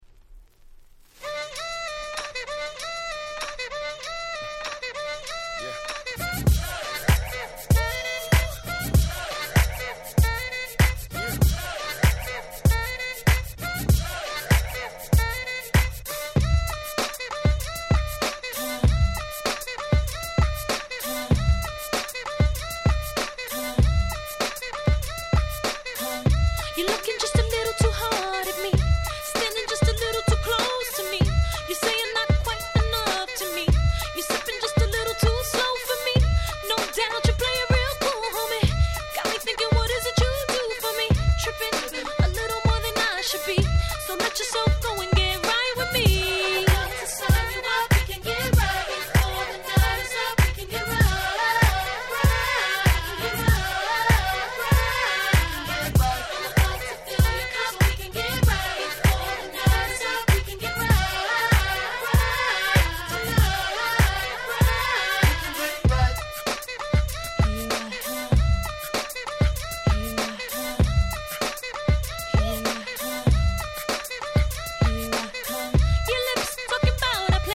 05' Super Hit R&B Album.